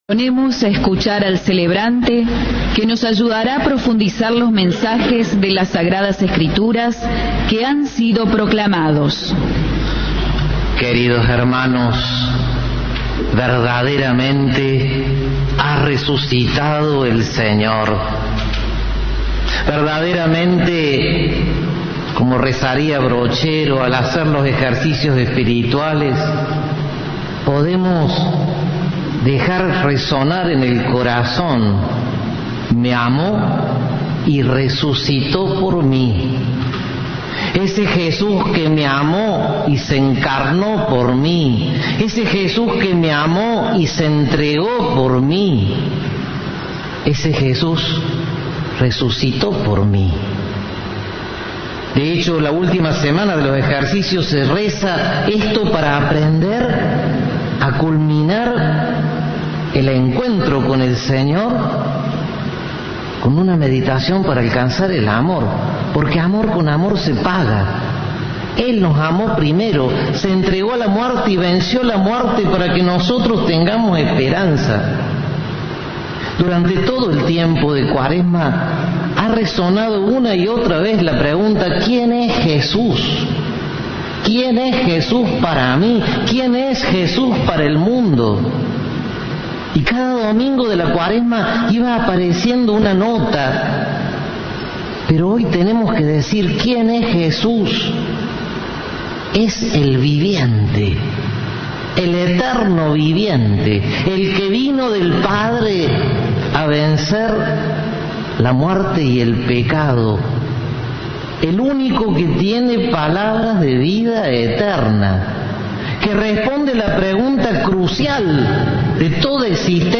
El sermón fue pronunciado por el arzobispo auxiliar de Córdoba, Pedro Torres.